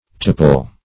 Tepal \Tep"al\, n. [F. t['e]pale, fr. p['e]tale, by